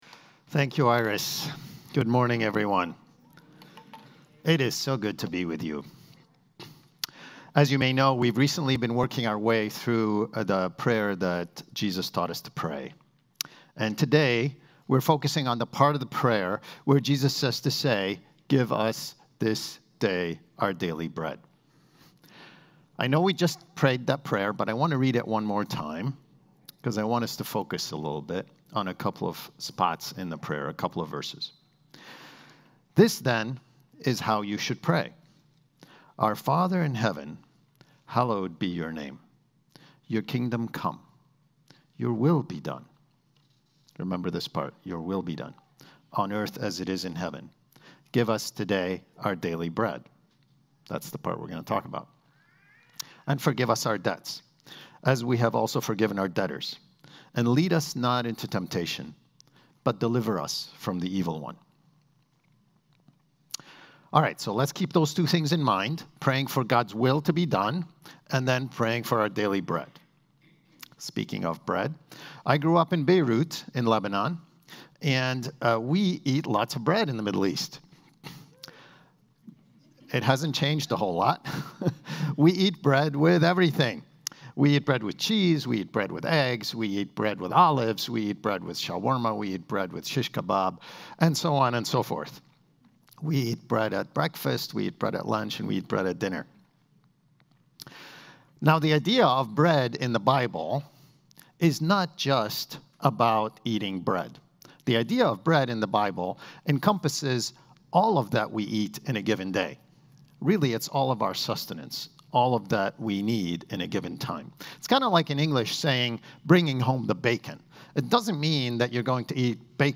brings us this morning's message.